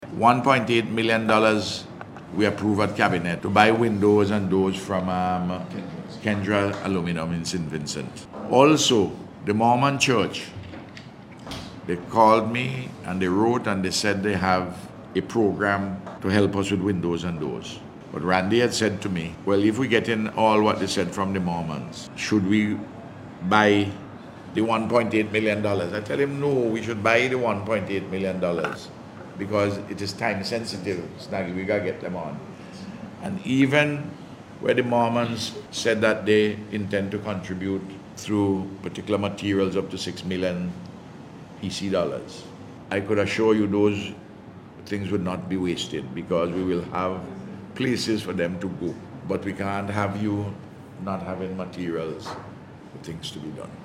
Speaking at a ceremony yesterday to welcome the Guyana Defense Force to the Southern Grenadines, Prime Minister Dr Ralph Gonsalves said this money will be used to buy items to outfit homes.